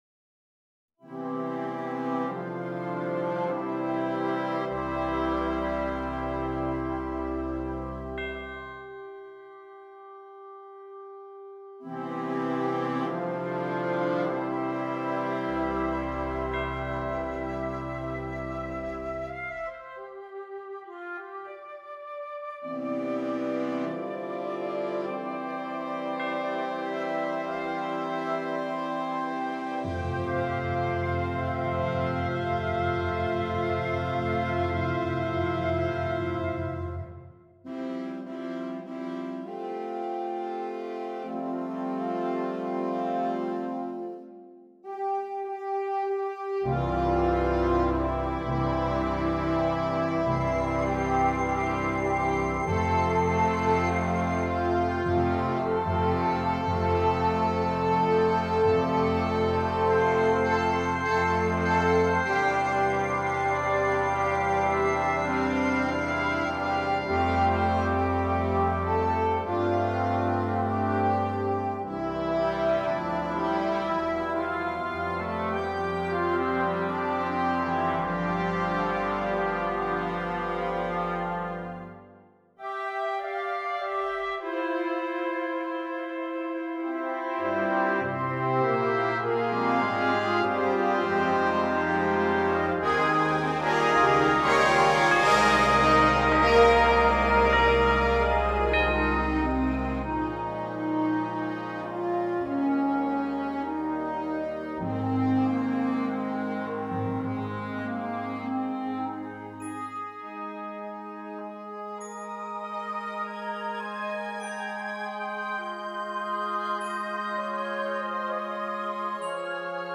Genre: Band
Flute 1
Oboe
English Horn
Bassoon
Tenor Saxophone
Euphonium
Tuba
Timpani [4 drums]
Percussion 1: Chimes, Glockenspiel
Percussion 2: Suspended Cymbal, Crash Cymbals
Percussion 3: 4 Concert Toms, Bass Drum